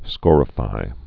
(skôrə-fī)